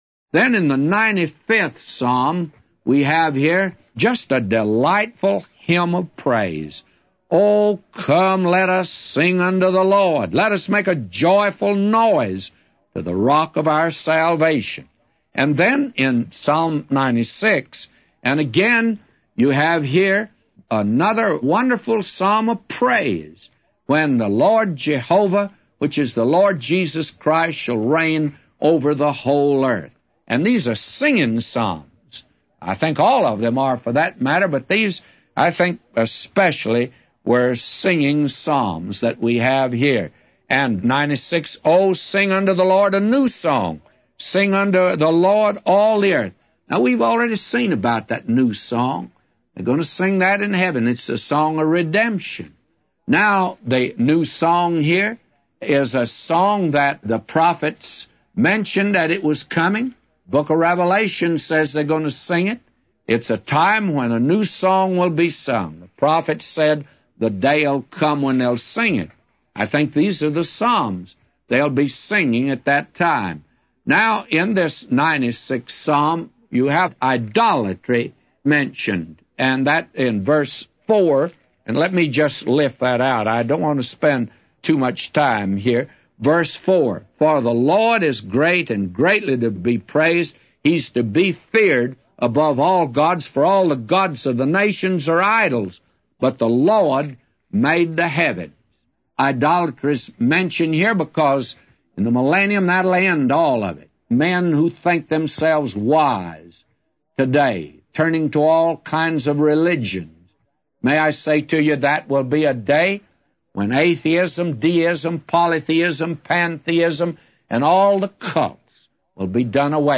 A Commentary By J Vernon MCgee For Psalms 95:1-999